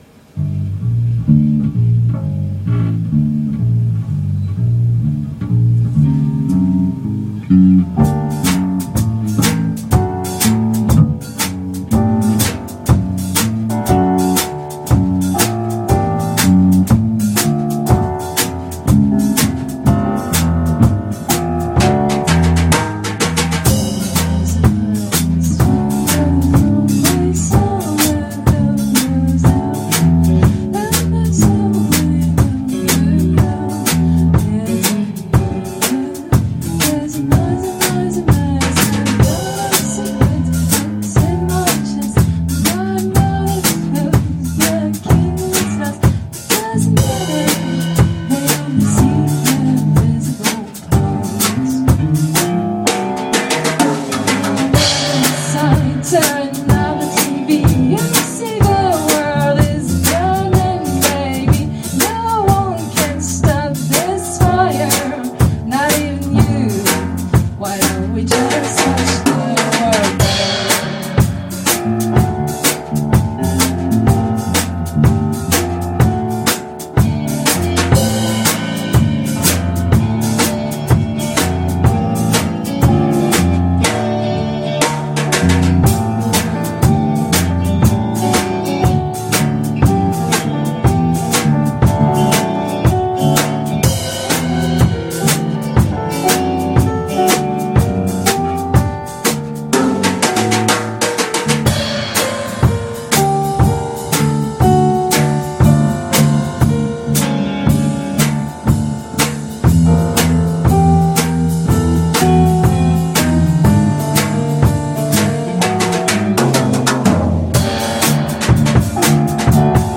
Impro
Walking Bass